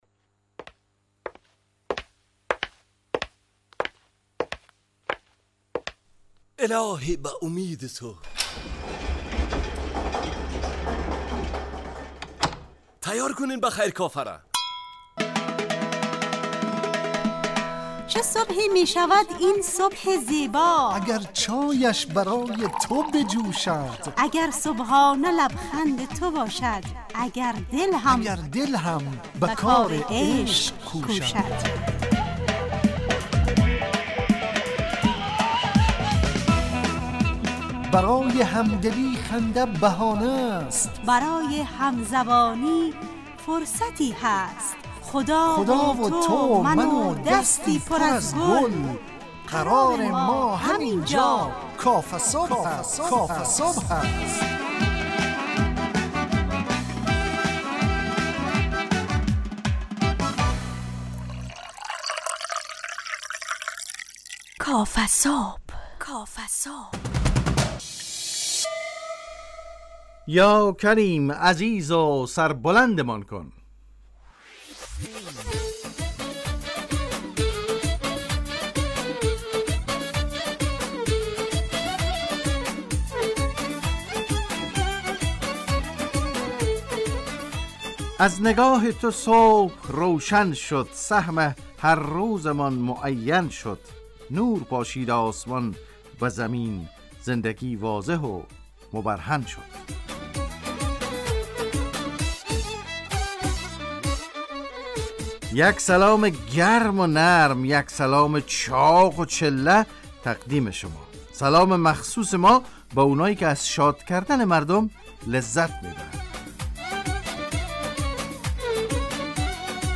کافه صبح - مجله ی صبحگاهی رادیو دری